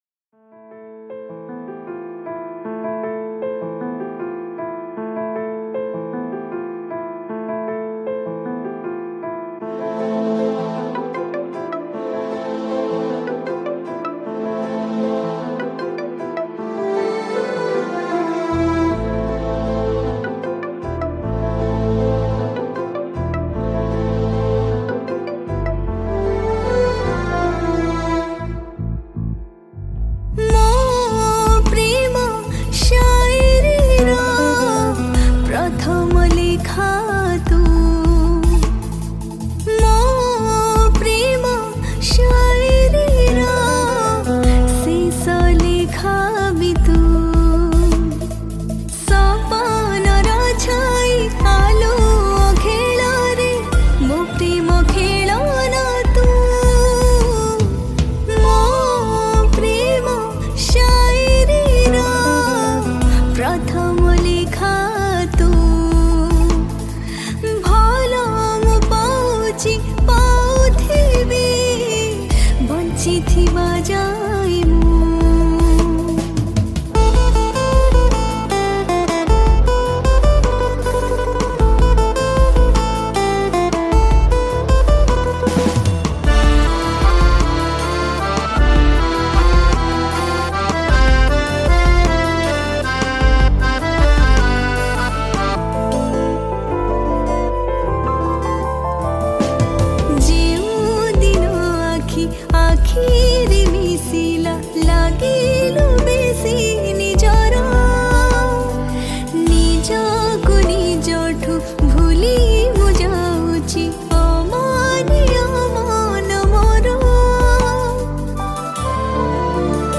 Romantic Song
Odia Songs